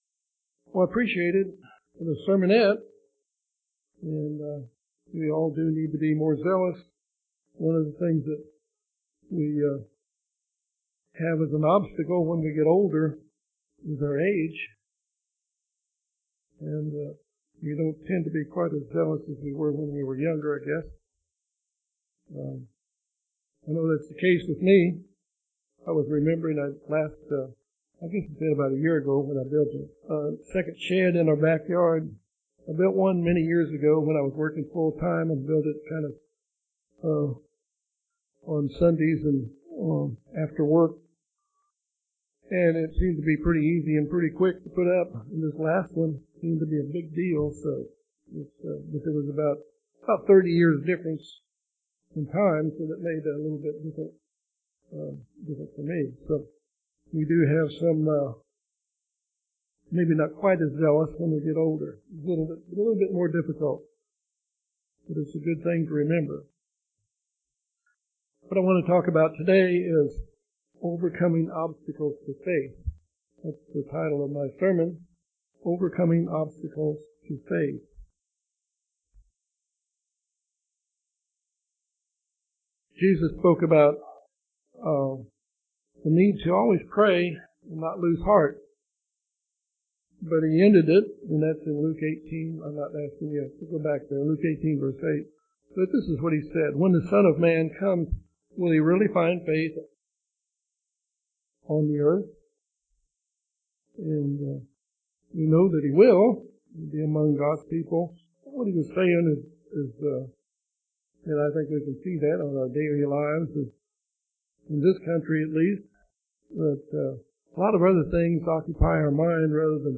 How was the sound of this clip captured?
Given in Little Rock, AR